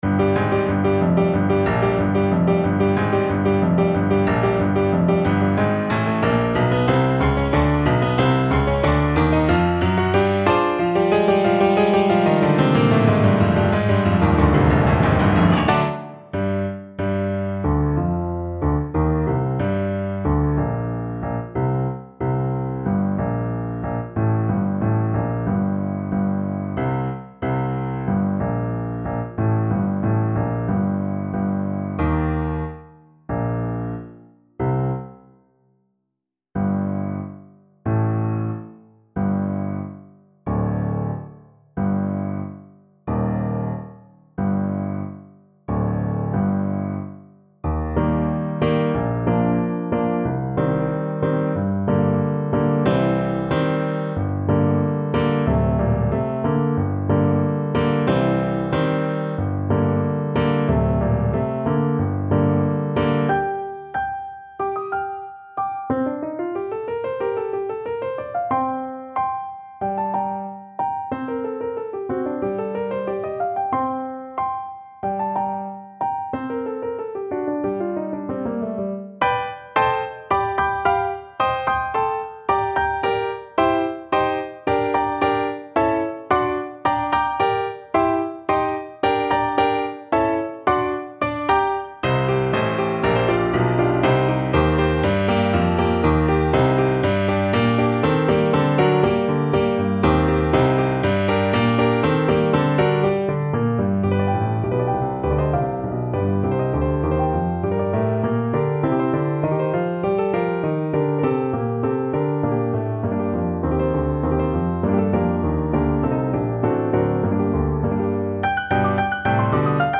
Allegro =92 (View more music marked Allegro)